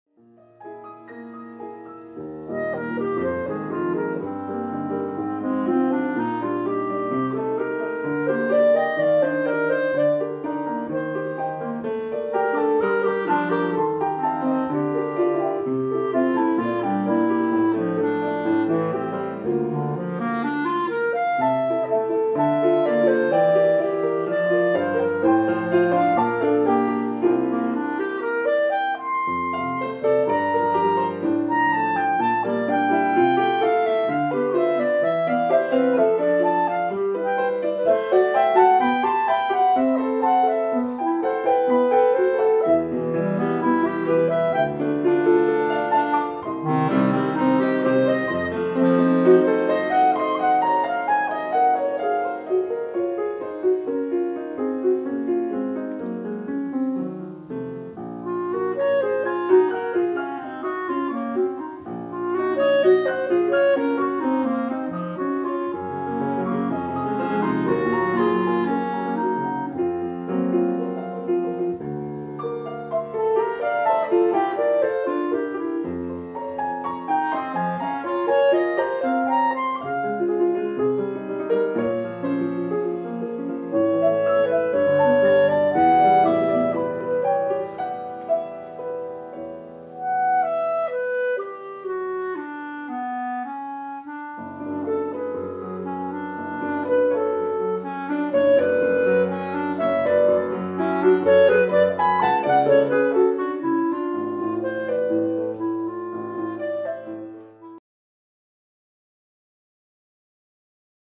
Chamber Ensembles
for clarinet and piano